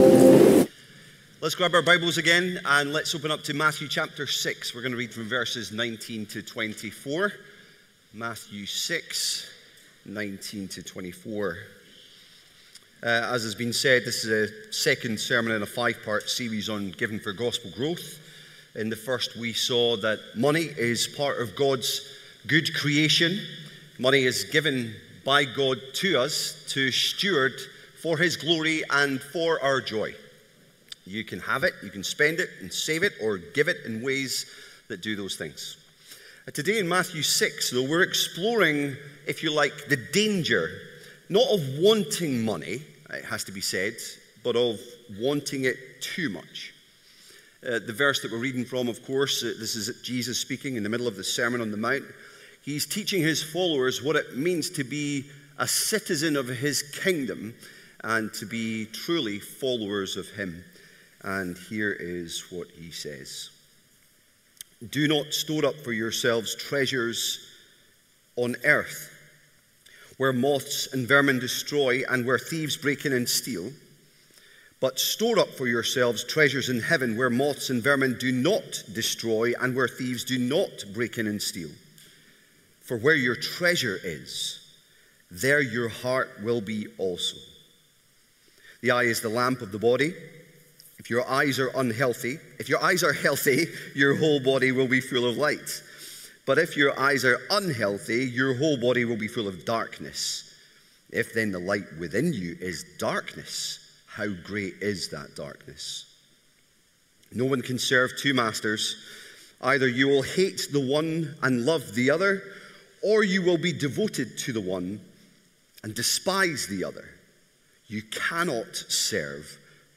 Sermons from Charlotte Chapel, Edinburgh